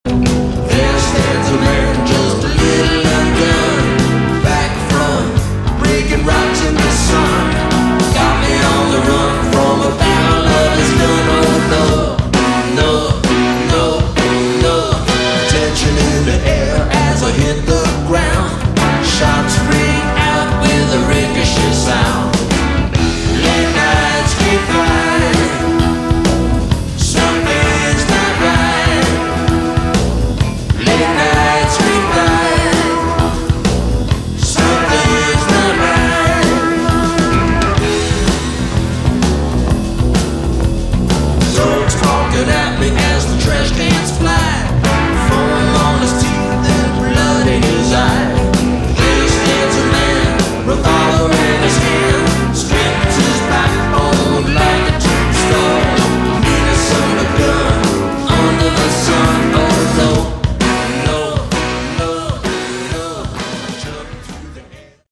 Category: Classic Hard Rock
Drums, Vocals
Bass, Guitar, Keyboards, Percussion, Vocals